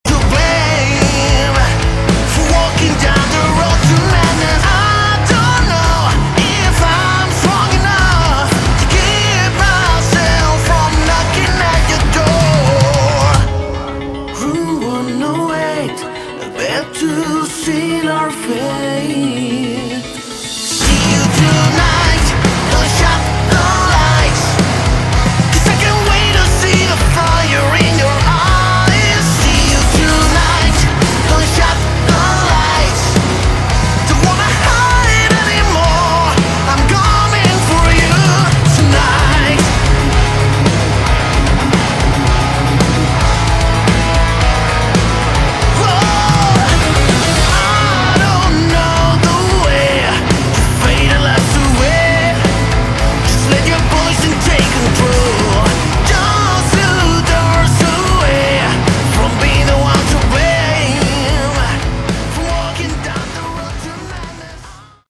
Category: Melodic Metal
vocals, guitars, bass, piano, programming
drums
backing vocals